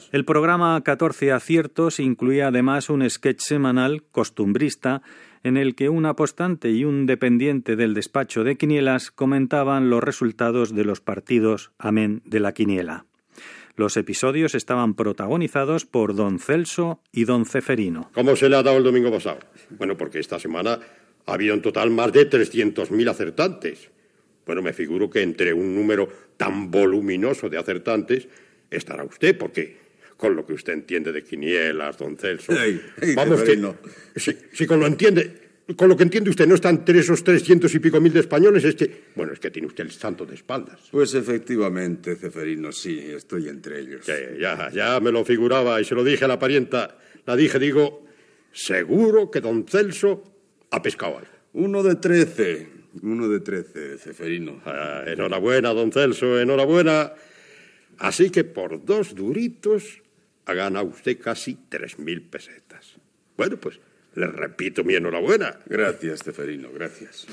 Diàleg ficcionat entre un apostant, don Celso, i el dependent d'un despatx d'administració de travesses, Ceferino.